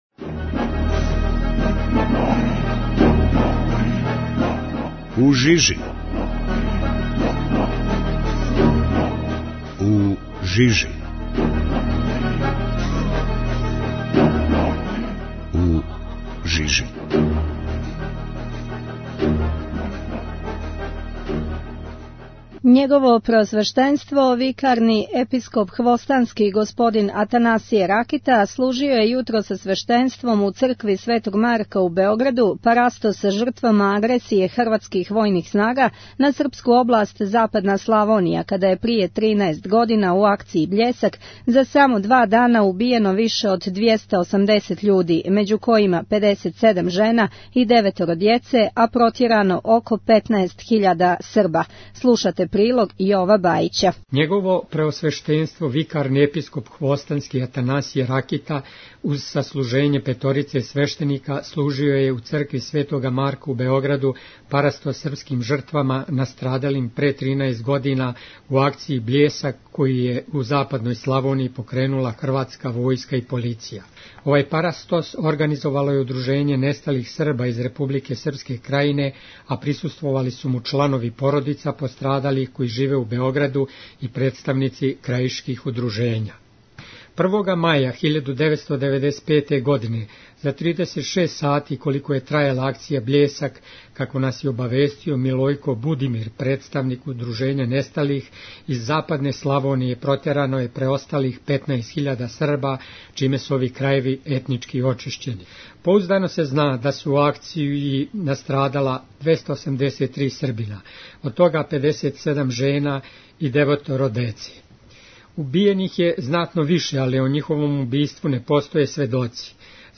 У цркви Светог Марка служен парастос свим жртвама војне акције Бљесак